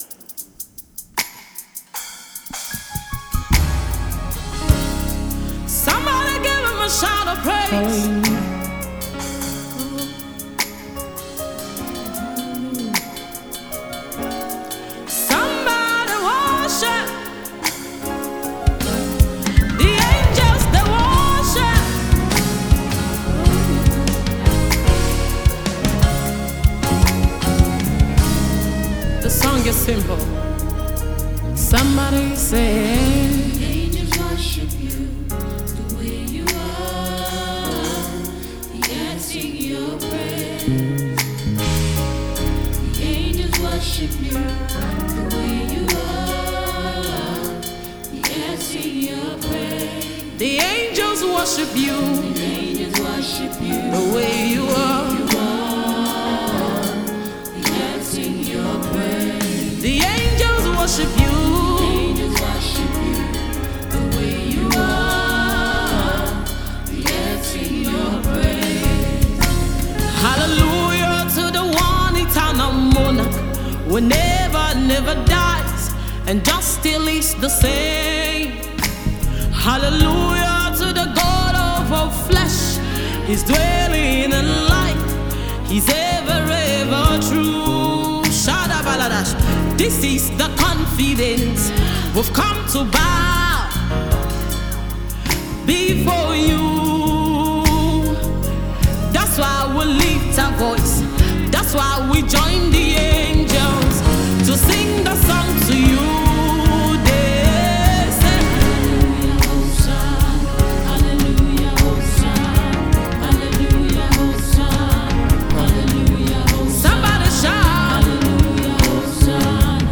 Gospel music minister